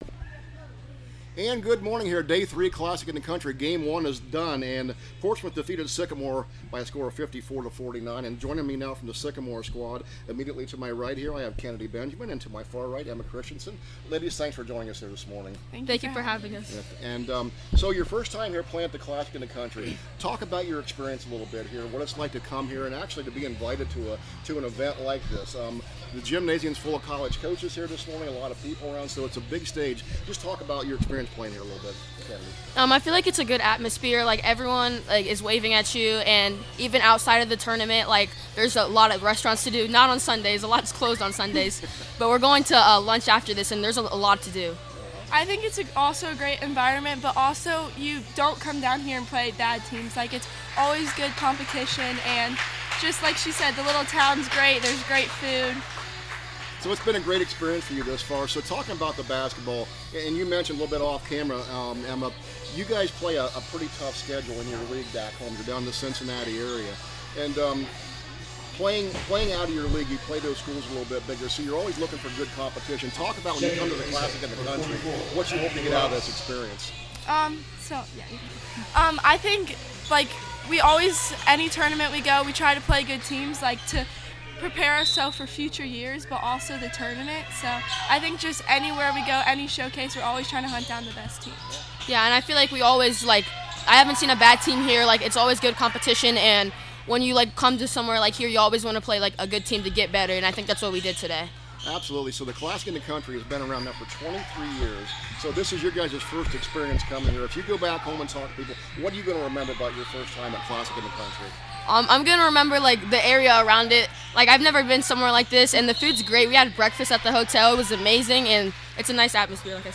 2026 CLASSIC – SYCAMORE PLAYERS INTERVIEW